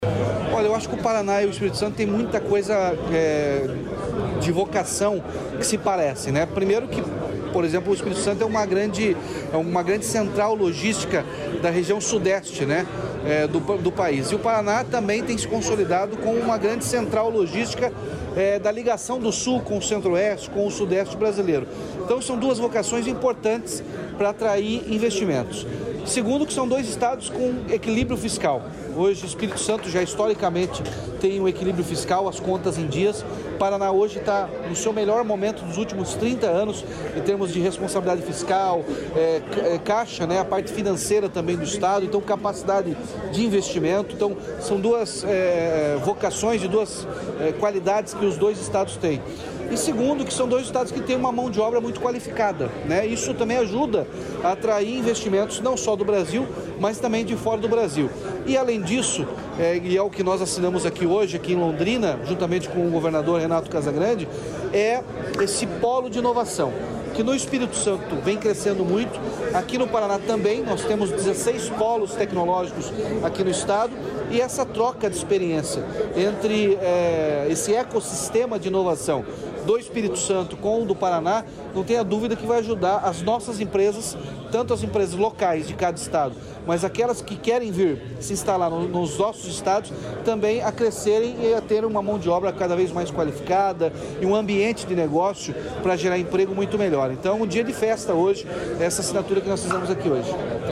Sonora do governador Ratinho Junior sobre a parceria entre o Paraná e o Espírito Santo